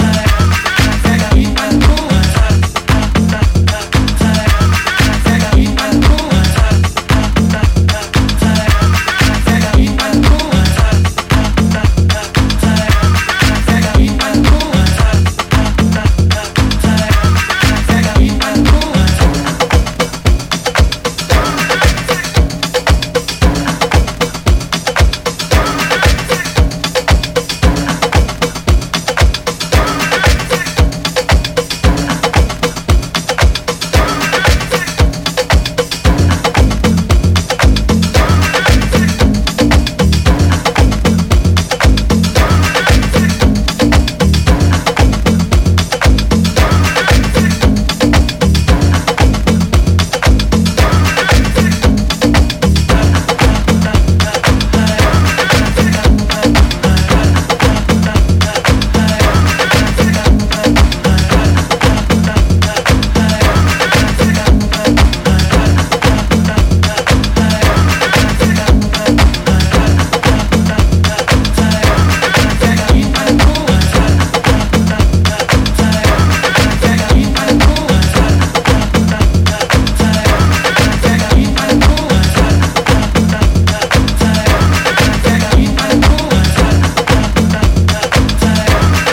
Hot house tracks!!!
Disco House